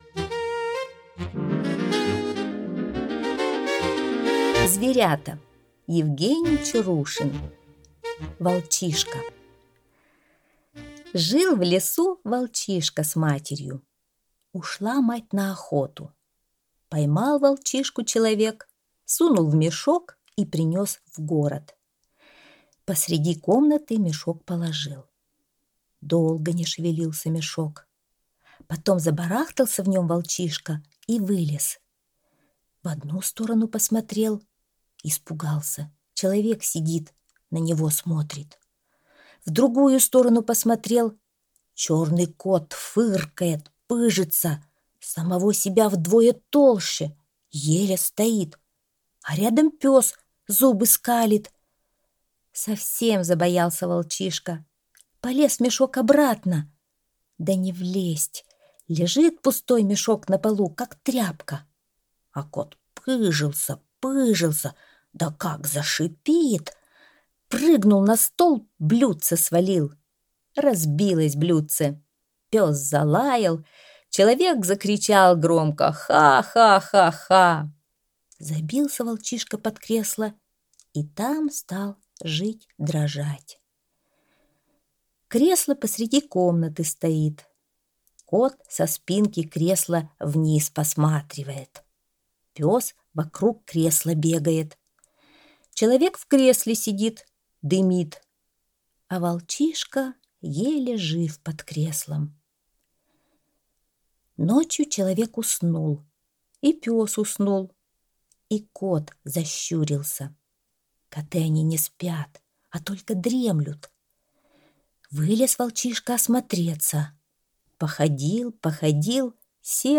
Зверята - аудио рассказ Чарушина - слушать онлайн